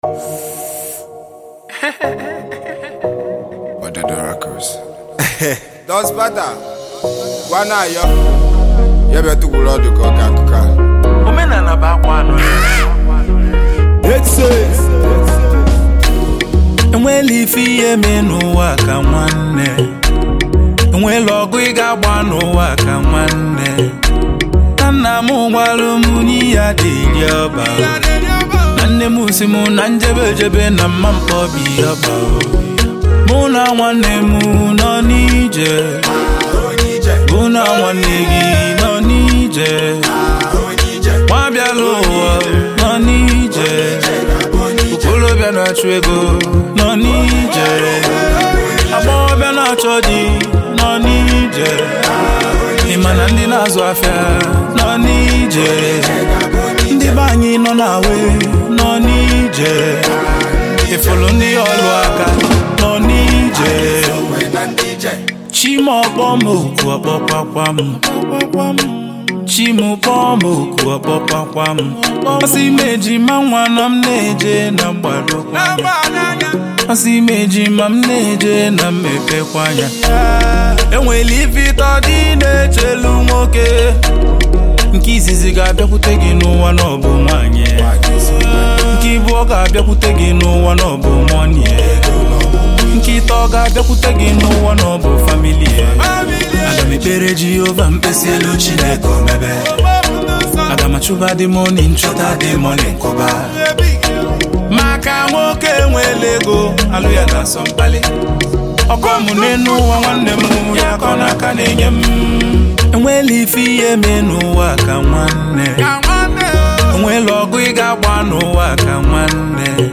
October 15, 2024 admin Highlife Music, Music 0